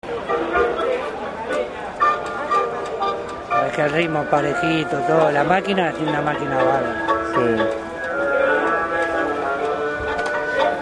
Hier ist das Grammophon zwar nicht zu sehen, aber in 5 Folgen zu hören.